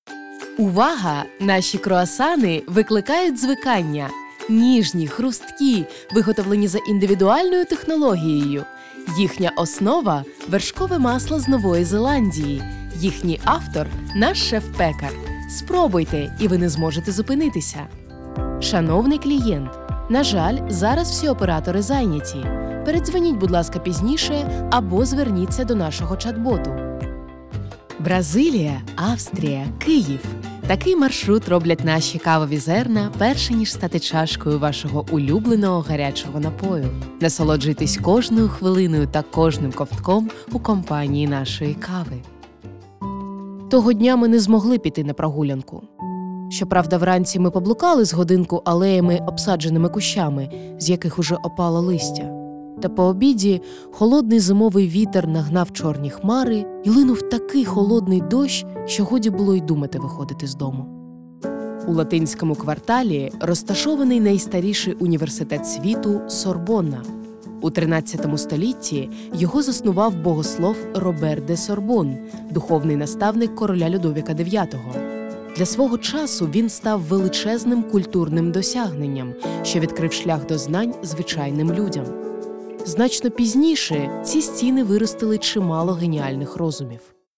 Native Ukrainian & Russian Voice Artist | English & French with Slavic Accent
Ukrainian Multi-Style Reel
My delivery ranges from calm, sophisticated narration and warm commercial tones to expressive, high-energy storytelling.